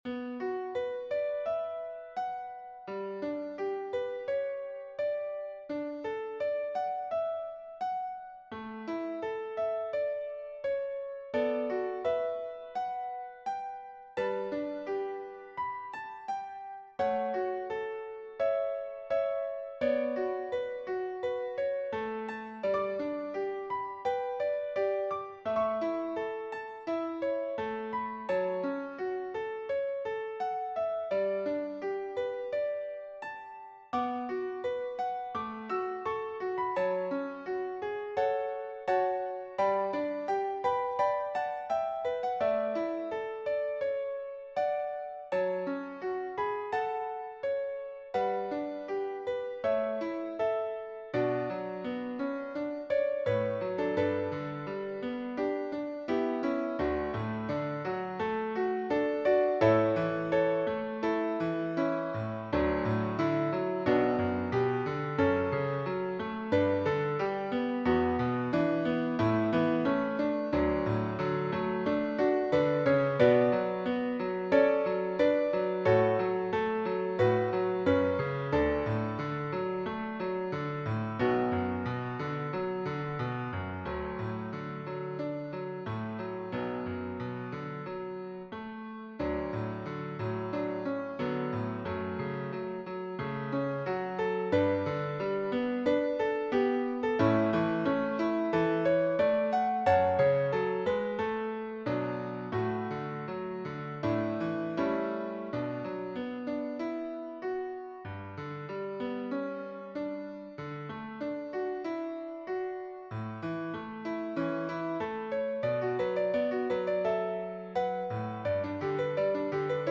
Voicing/Instrumentation: Piano Prelude/Postlude , Piano Solo